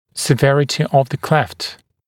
[sɪ’verətɪ əv ðə kleft][си’вэрэти ов зэ клэфт]тяжесть расщелины, выраженность ращелины